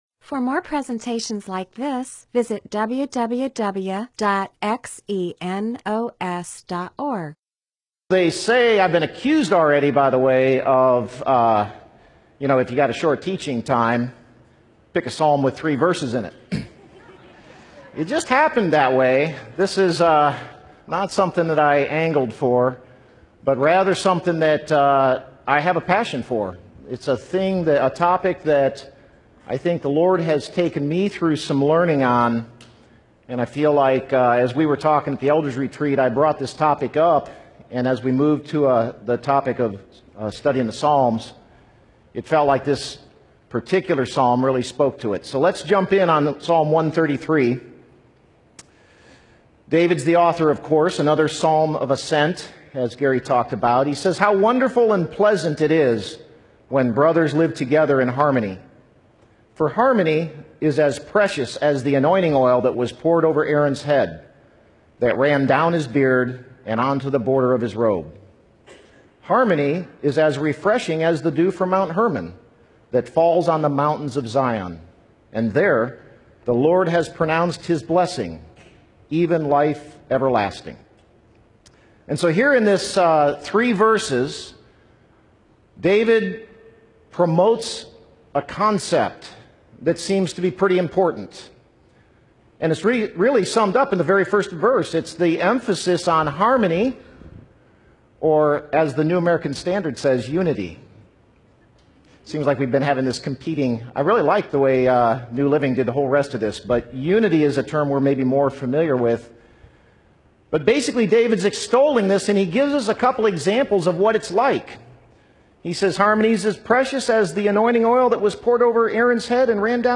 MP4/M4A audio recording of a Bible teaching/sermon/presentation about Psalms 133.